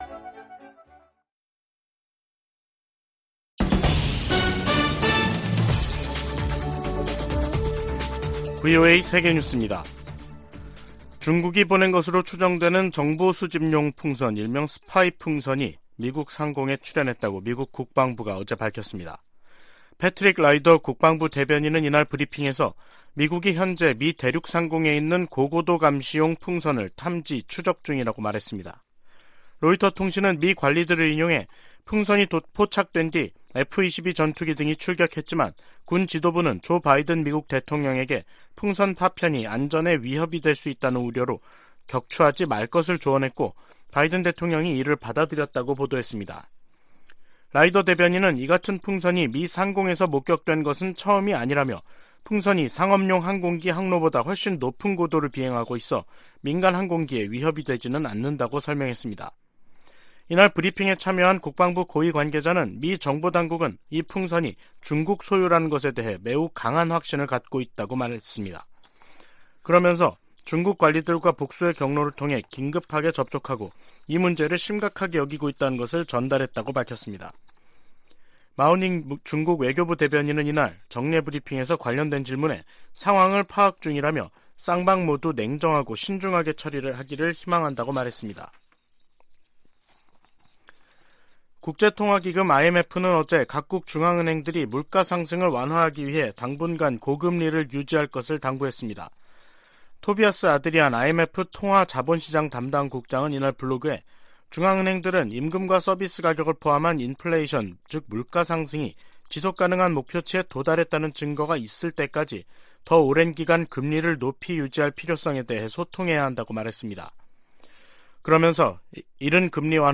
VOA 한국어 간판 뉴스 프로그램 '뉴스 투데이', 2023년 2월 3일 2부 방송입니다. 미국과 한국 외교장관들은 오늘 워싱턴에서 열리는 회담에서 북한 문제는 물론 경제와 외교 등 다양한 주제를 다룰 것이라고 밝혔습니다. 미국 백악관은 북한의 미한 연합훈련 비난에 대해 북한에 대한 적대적 의도가 없는 통상적인 훈련이라고 반박했습니다.